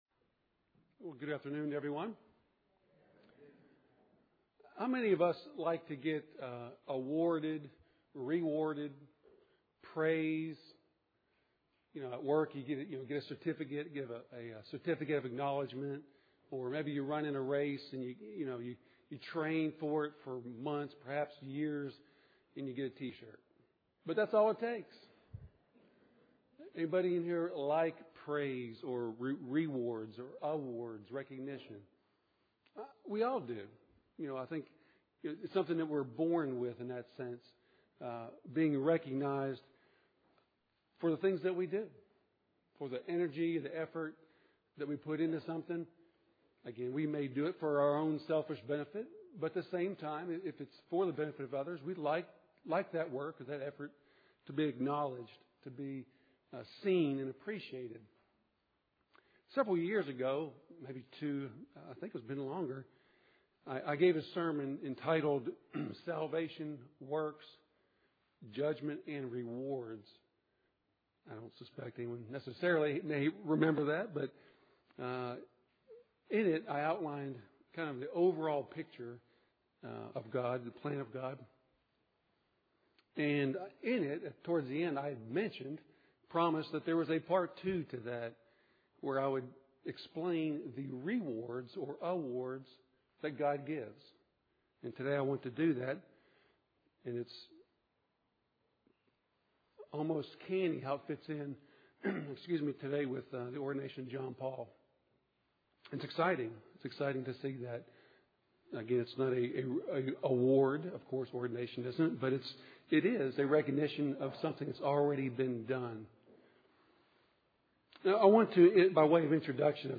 Given in Nashville, TN
Related Reading: Booklet: Tools For Spiritual Growth UCG Sermon Studying the bible?